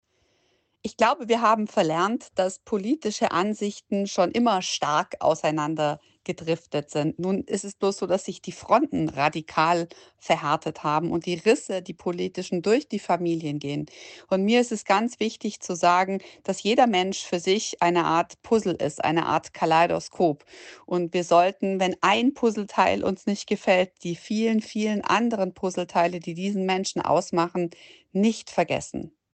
Wir sprechen mit Caro Matzko, Journalistin und TV-Moderatorin.